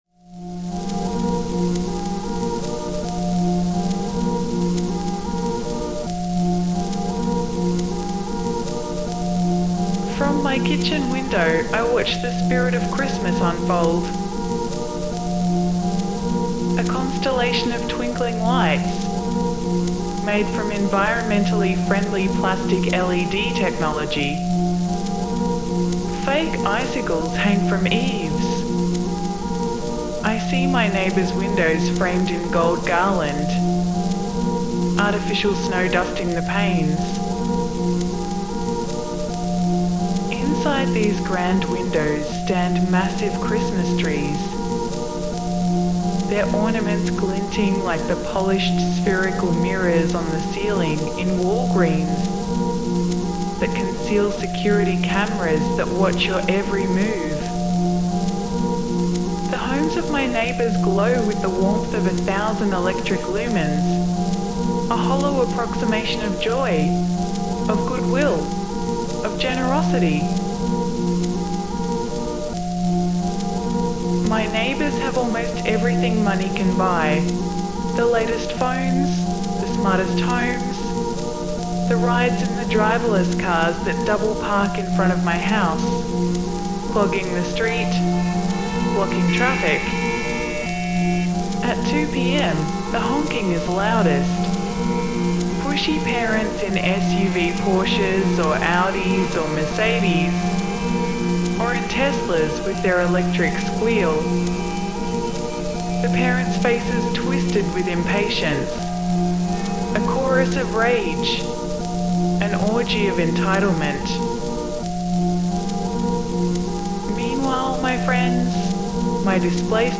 The format is simple: I’ll share some thoughts currently on my mind, and then read you a short story I wrote. No sponsors, no endless calls to action, and definitely no engagement tactics.